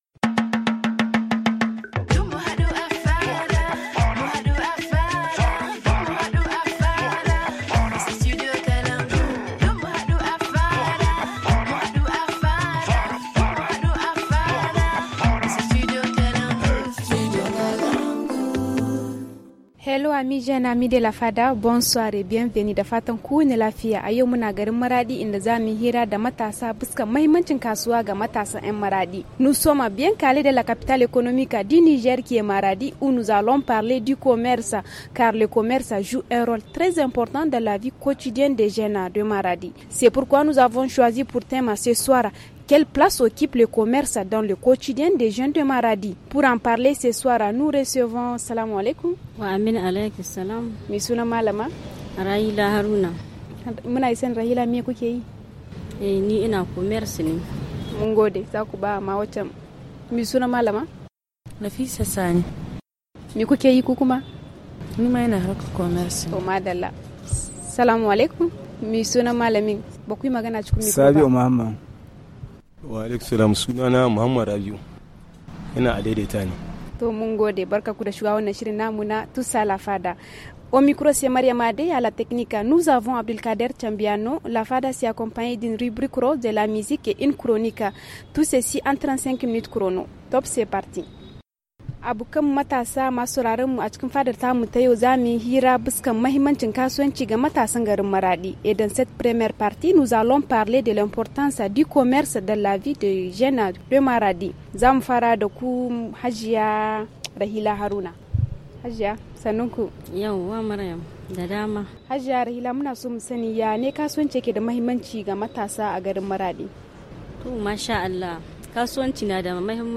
jeune commerçante
jeune entrepreneur